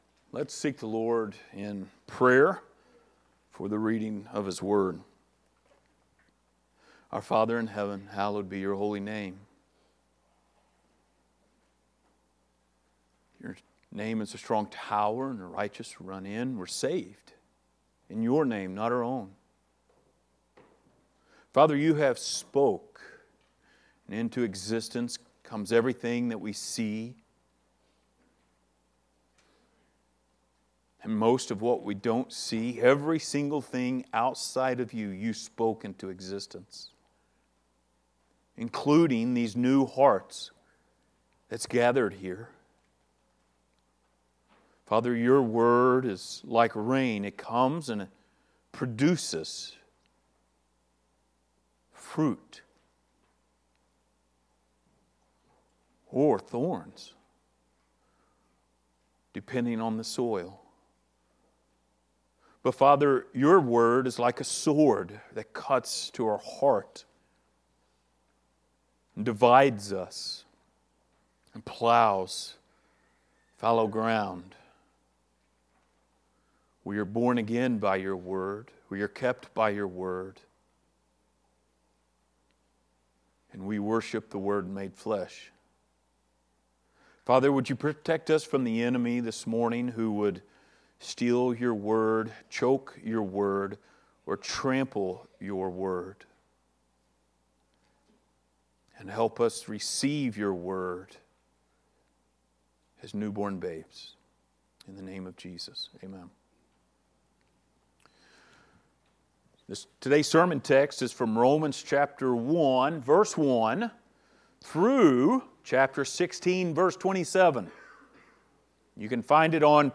This is a reading of the Book of Romans
Service Type: Sunday Morning